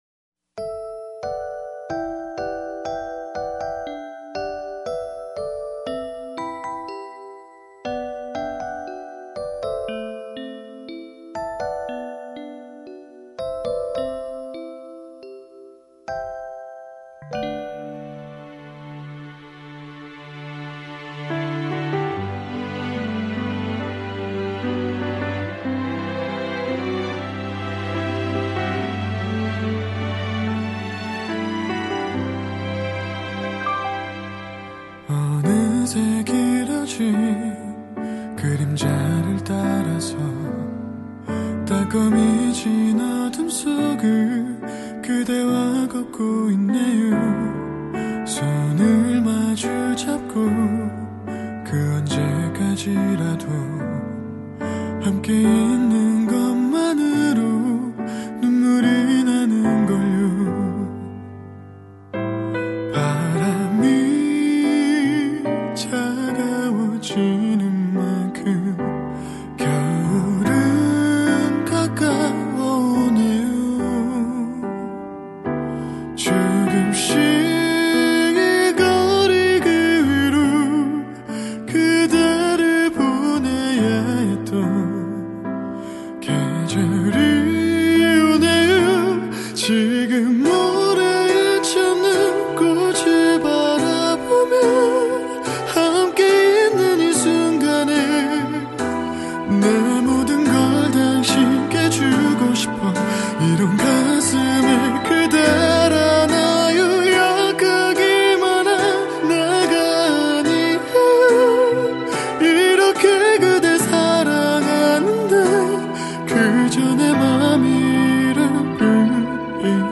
Korean Male Version